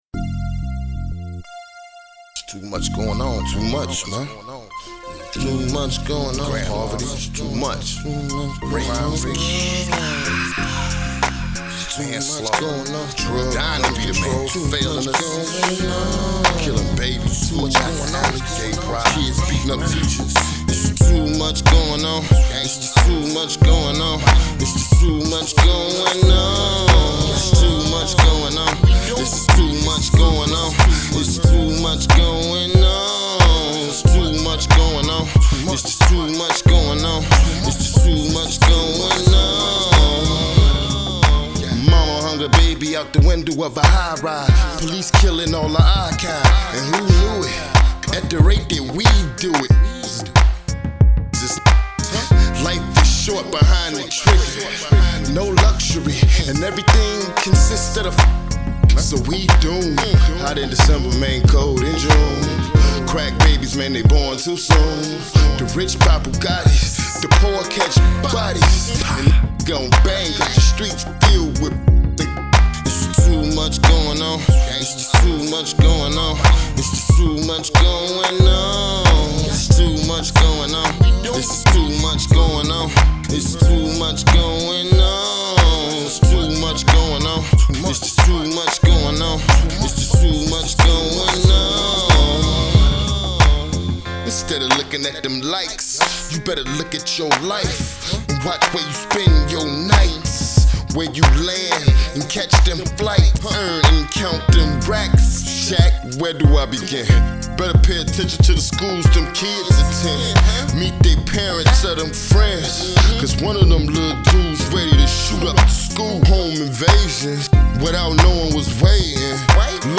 new musical commentary on the current state of the world